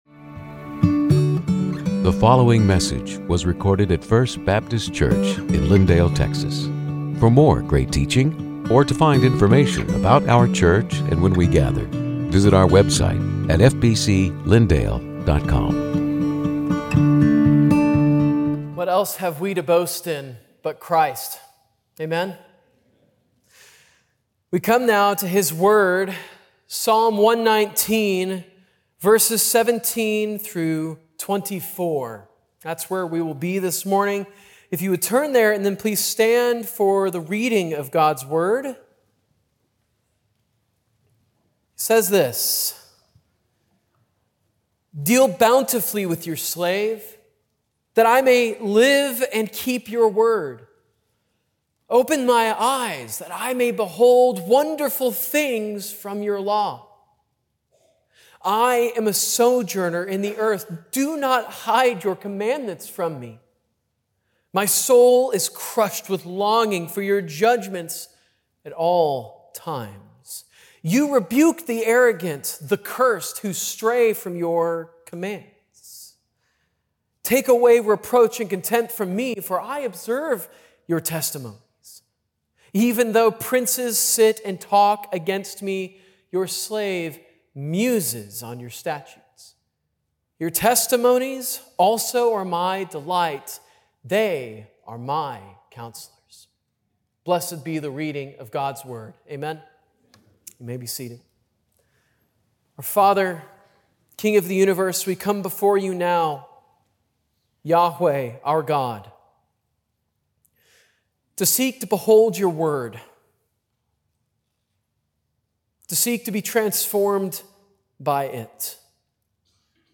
Sermons › Psalm 119:17-24